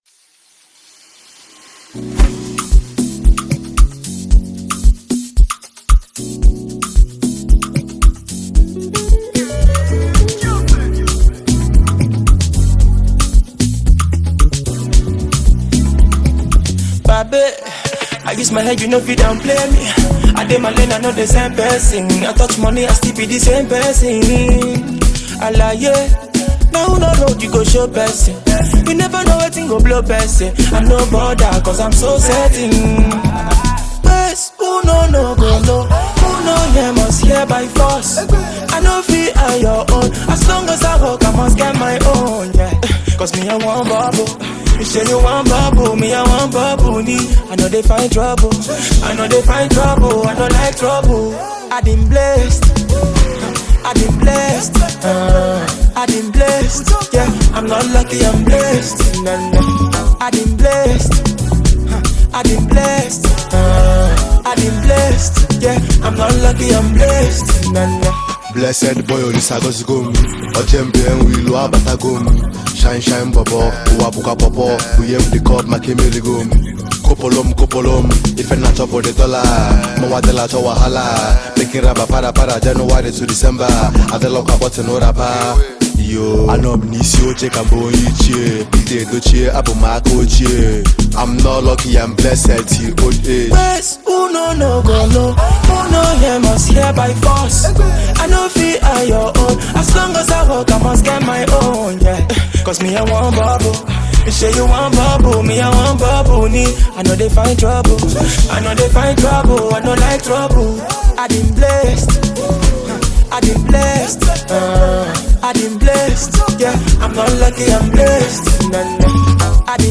a musical prodigy and rap artist